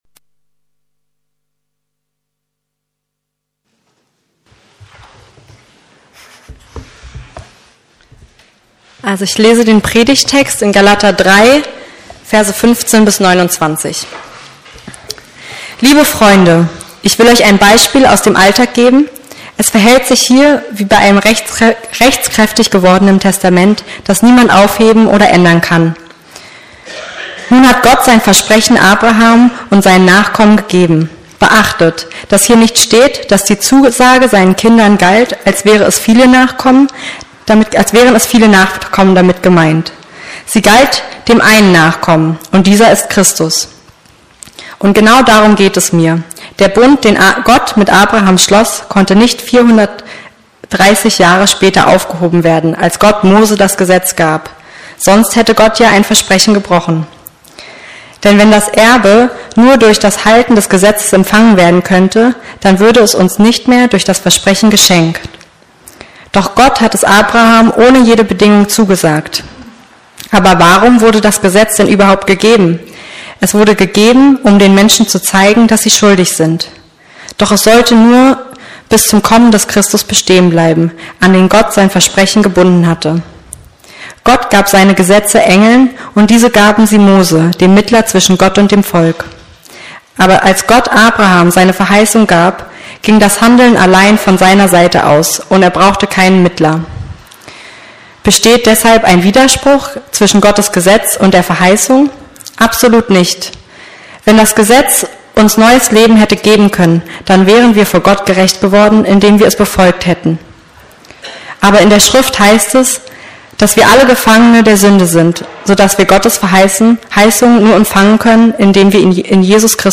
Das Gesetz, Jesus und Ich ~ Predigten der LUKAS GEMEINDE Podcast